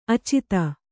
♪ acita